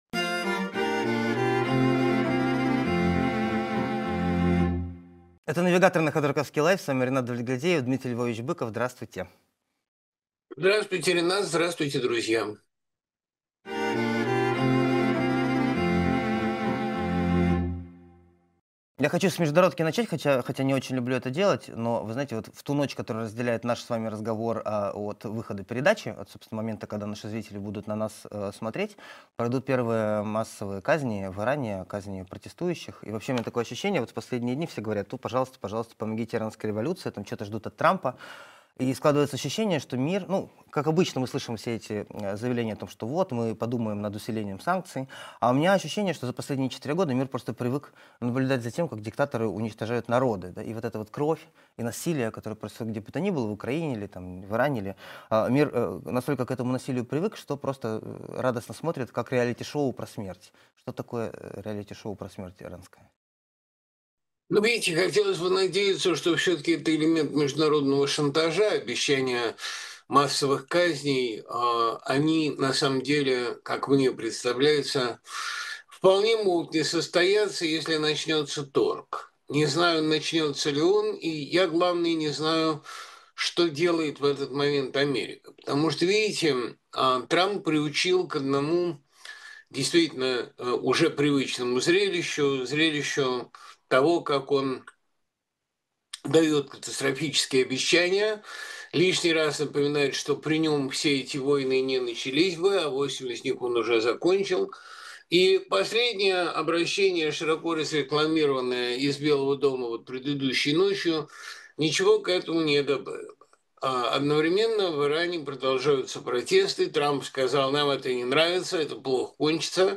Дмитрий Быков поэт, писатель, журналист